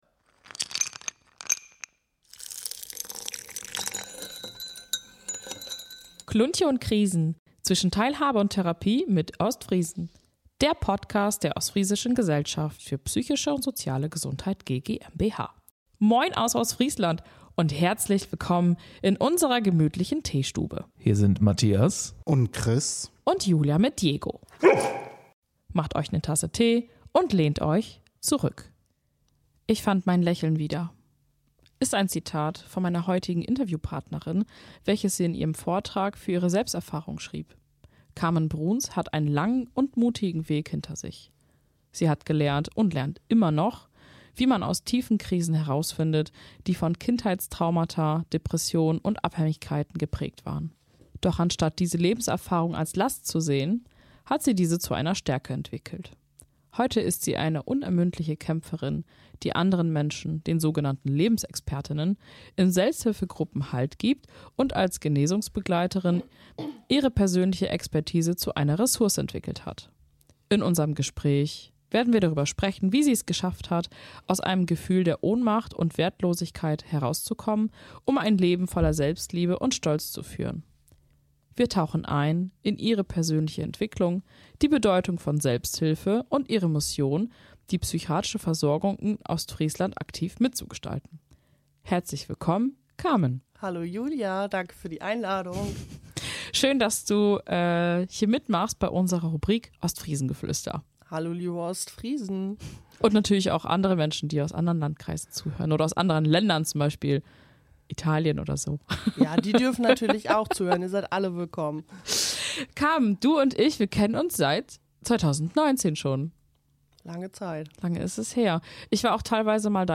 Heute arbeitet sie als Genesungsberaterin und unterstützt andere Menschen dabei, ihren eigenen Weg zu finden. Offen, ehrlich und mit einer guten Portion norddeutscher Lockerheit teilt sie ihr Wissen – und macht Mut, dass Veränderung möglich ist Mehr